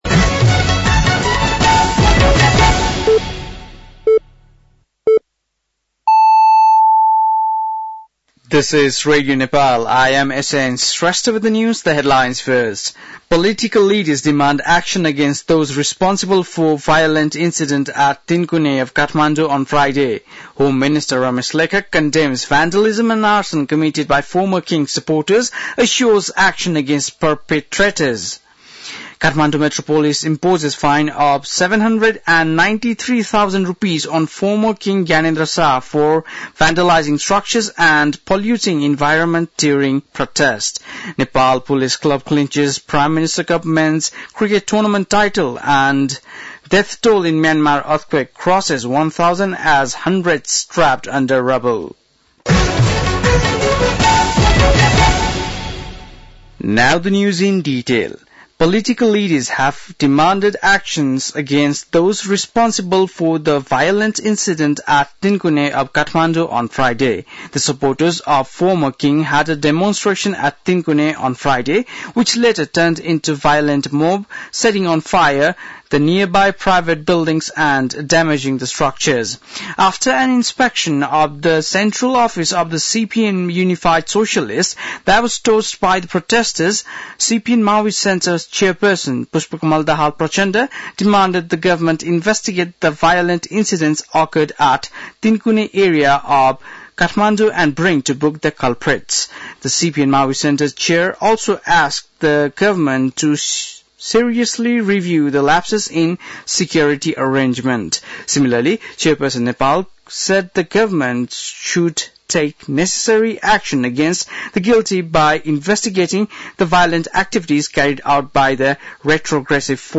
बेलुकी ८ बजेको अङ्ग्रेजी समाचार : १६ चैत , २०८१
8-pm-News-12-16.mp3